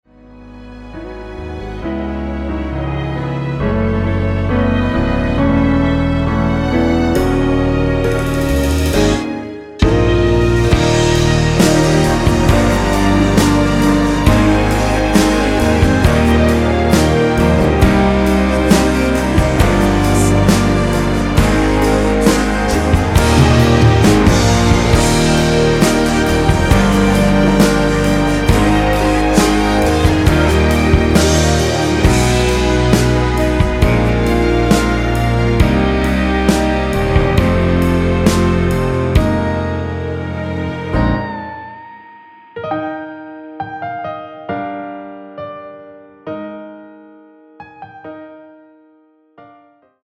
이곡의 코러스는 미리듣기에 나오는 부분밖에 없으니 참고 하시면 되겠습니다.
원키에서(-1)내린 코러스 포함된 MR입니다.(미리듣기 확인)
Db
앞부분30초, 뒷부분30초씩 편집해서 올려 드리고 있습니다.